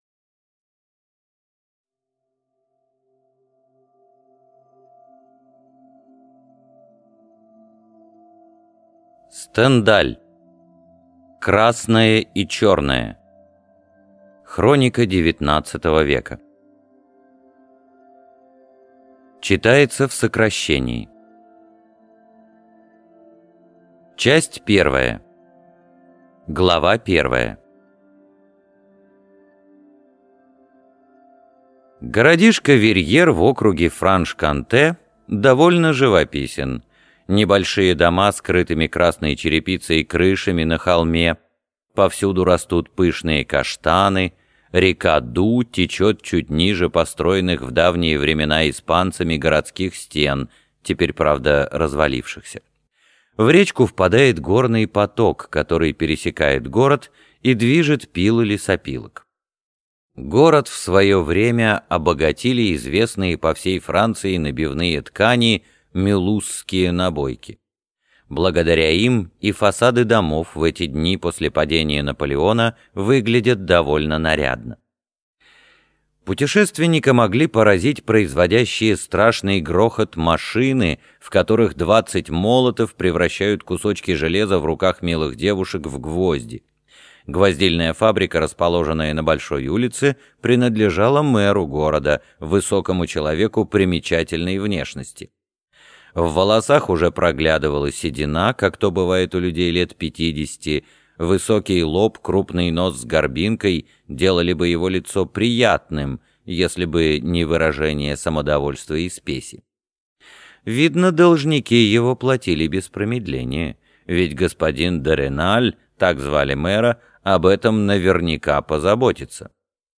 Аудиокнига Красное и черное (сокращенный пересказ) | Библиотека аудиокниг